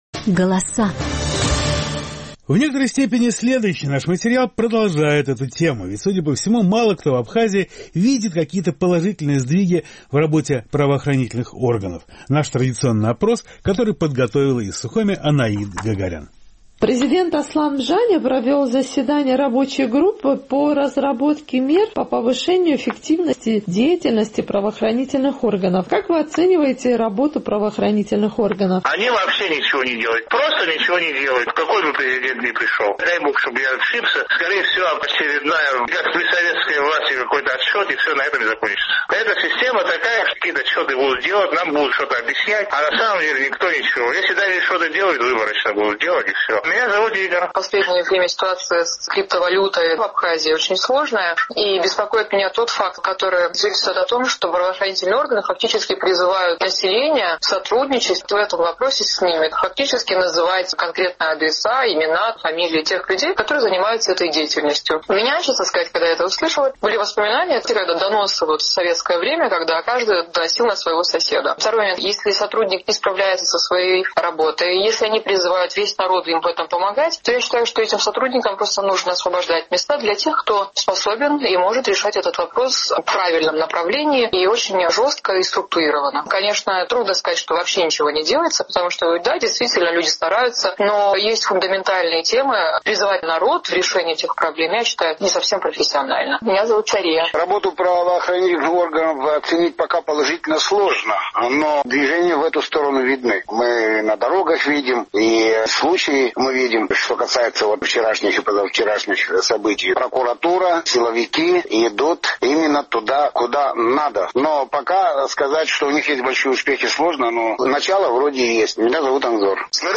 Судя по всему, мало кто в Абхазии видит какие-то положительные сдвиги в работе правоохранительных органов. Наш традиционный опрос.